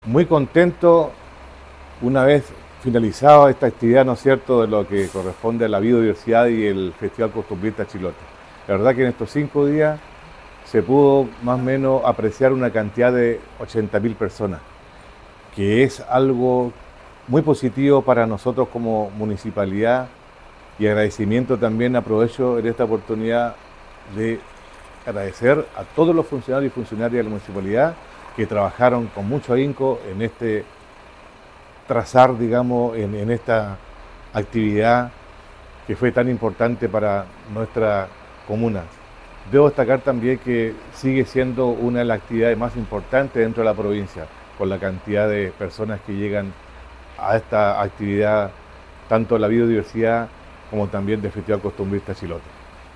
Por su parte, el alcalde de Castro, Baltazar Elgueta Cheuquepil, resaltó que la presencia de 80.000 personas en ambas actividades:
alcalde-balance-festival-costumbrista.mp3